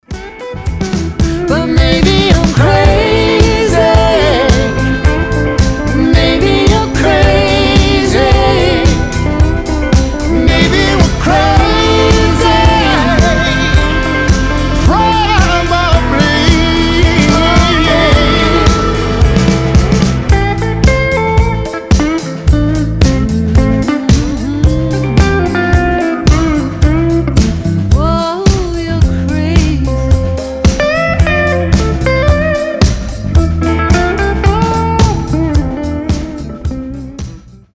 • Качество: 320, Stereo
гитара
дуэт
Cover
блюз
Блюз-рок